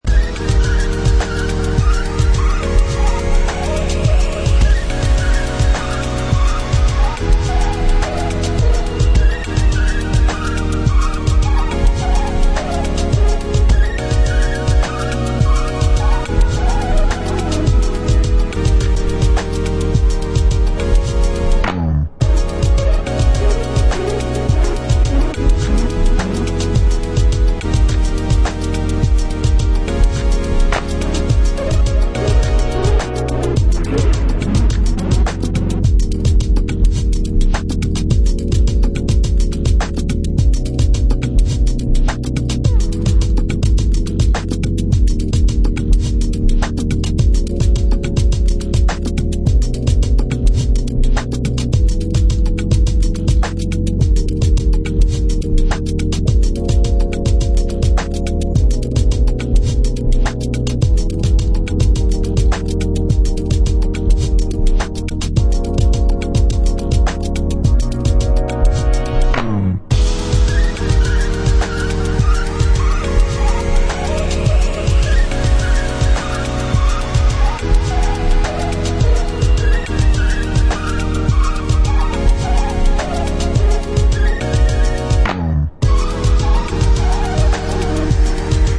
House Bass